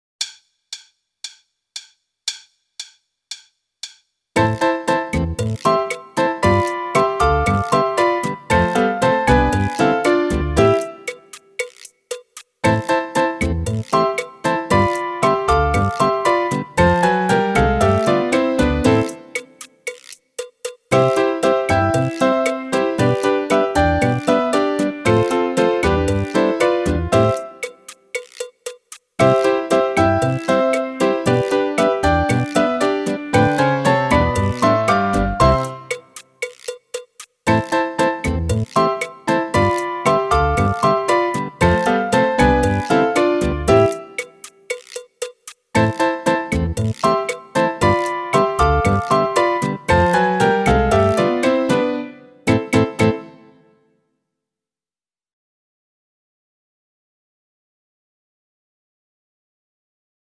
Meespeel CD
32. Cha-cha